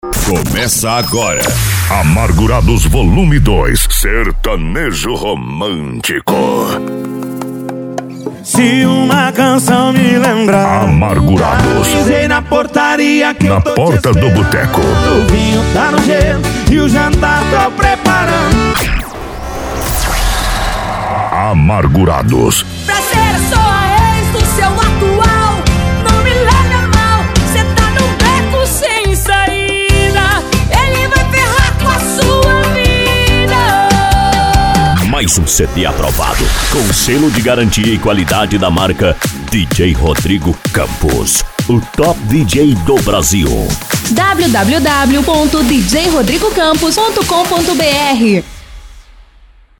Sertanejo Romântico